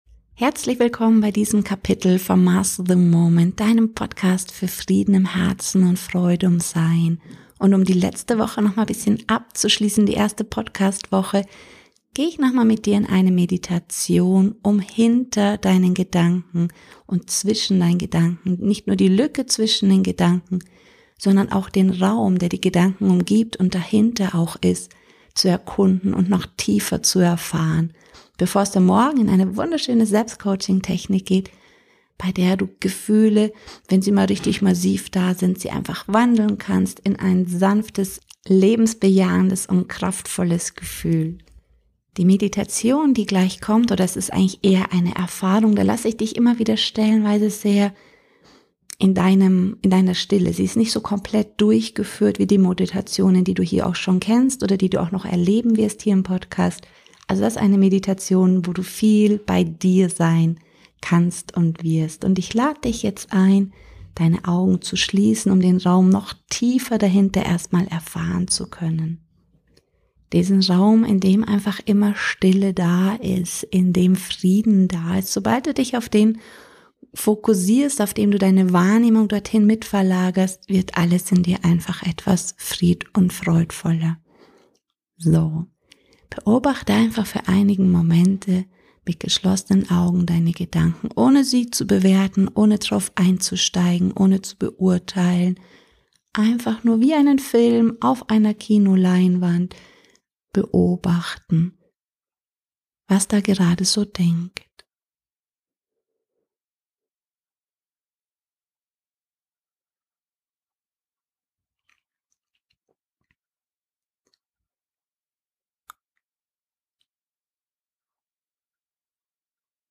Meditation - Ent-decke das Wohlgefühl hinter Deinen Gedanken - lebe grundlos glücklich